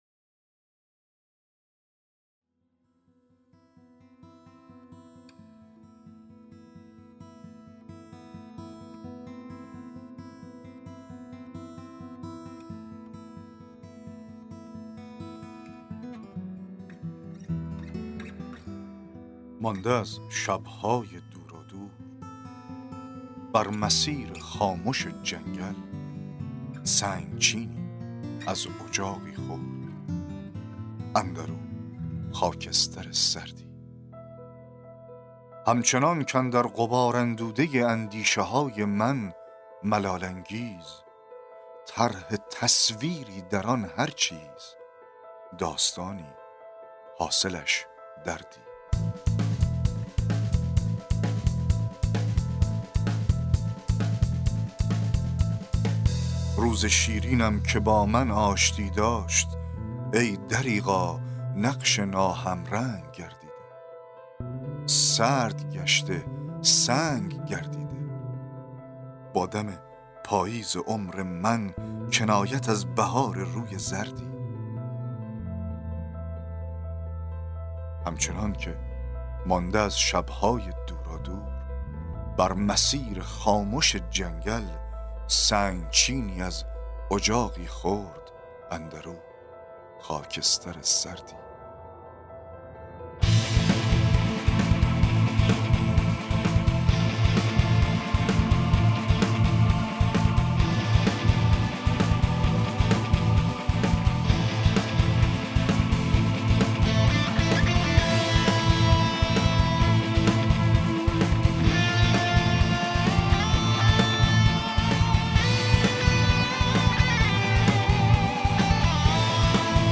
شعر اجاق سرد | دکلمه، متن،تحلیل و معنی شعر